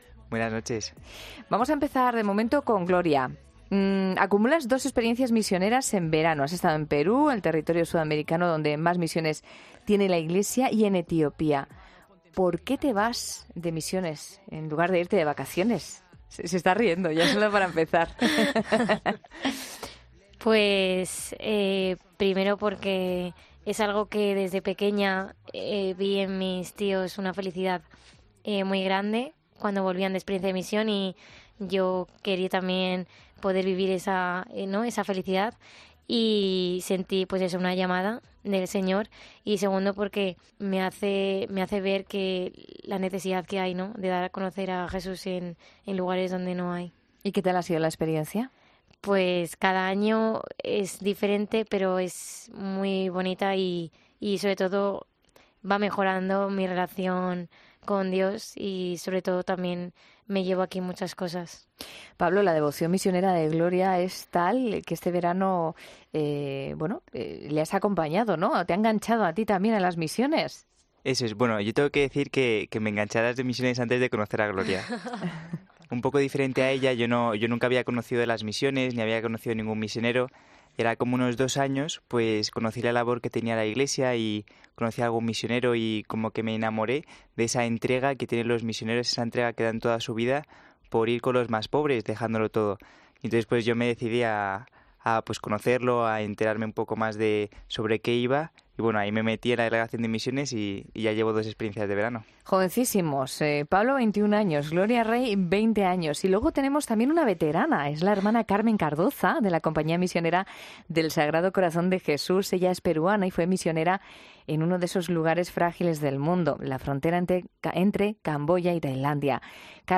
Dos misioneros veinteañeros y una hermana de la Compañía del Sagrado Corazón de Jesús, en 'La Noche'